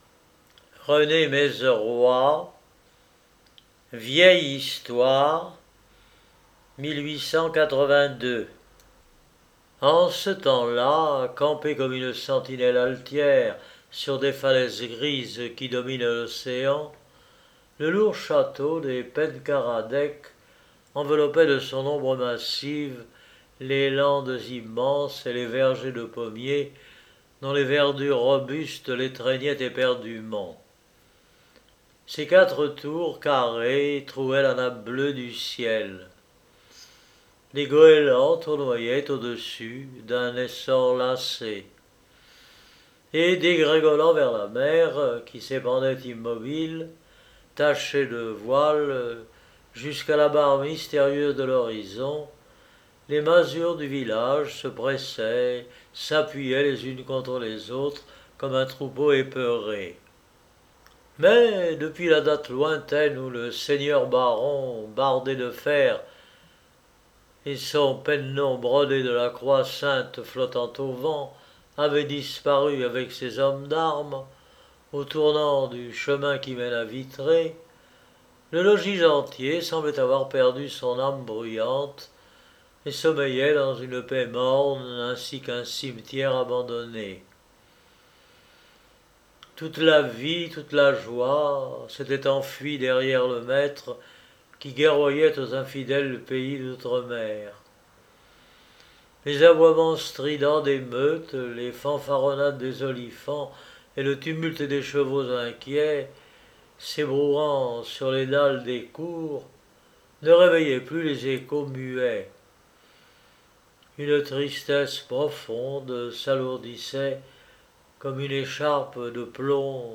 Genre : Nouvelles